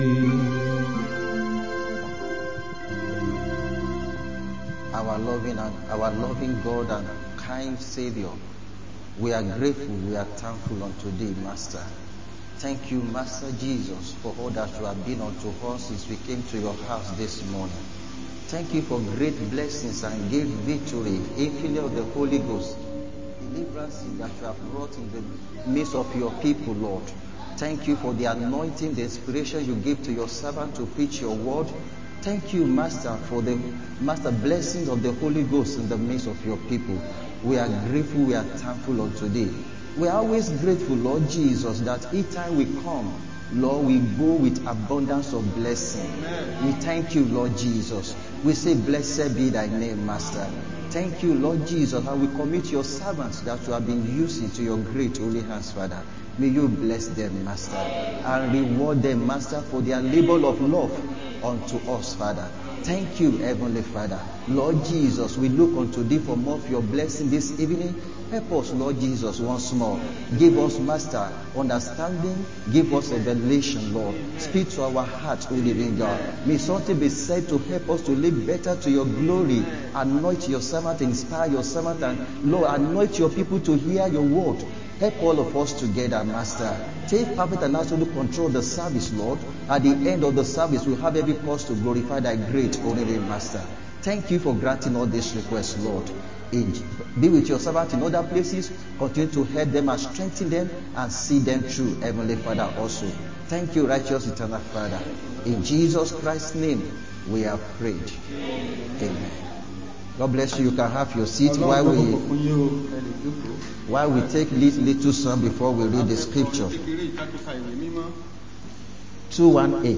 Sunday Afternoon Service 26-10-25